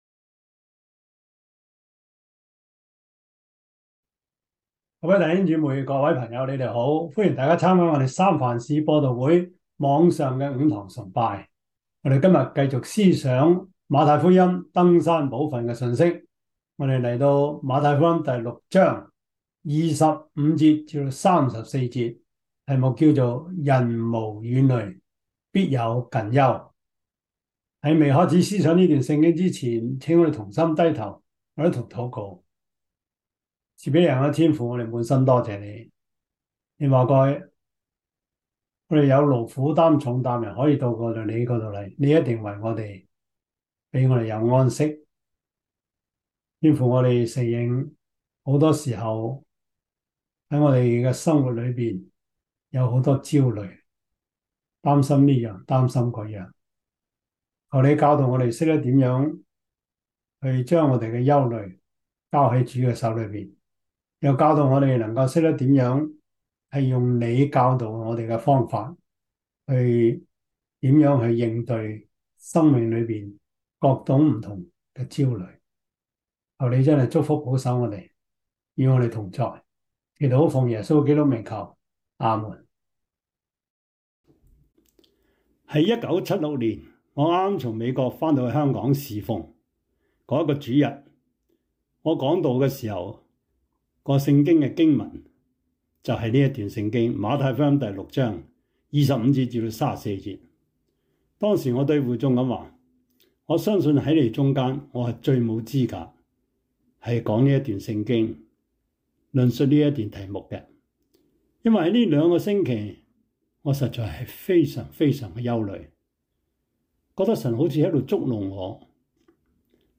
馬太福音 6:25-34 Service Type: 主日崇拜 馬太福音 6:25-34 Chinese Union Version